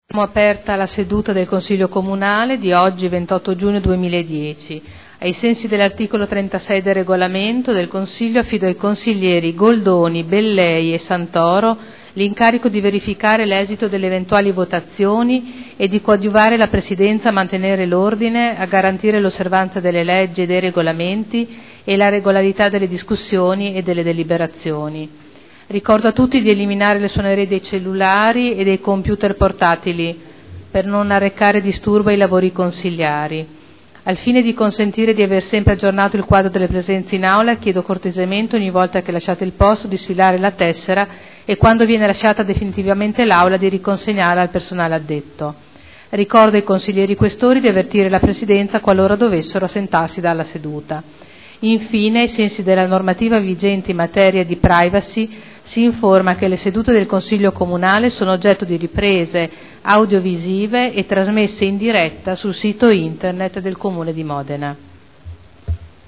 Apertura del Consiglio Comunale